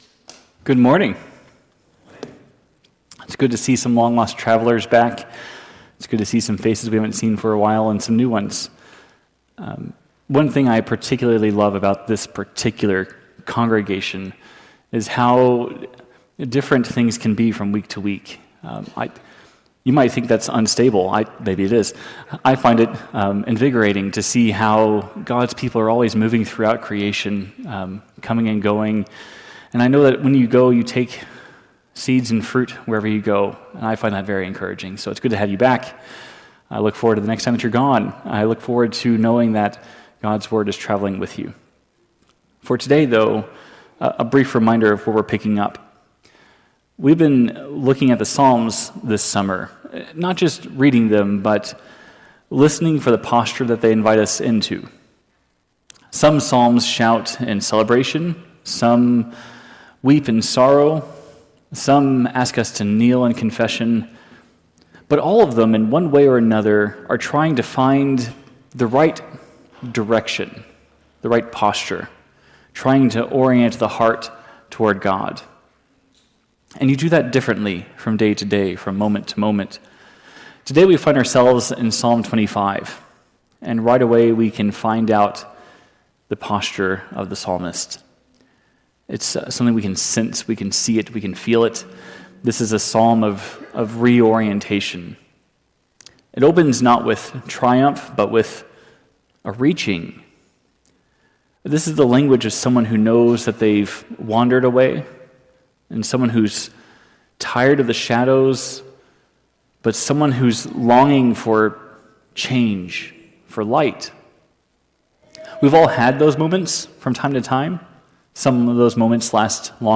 This sermon calls for repentance and a recommitment to walking in God’s grace.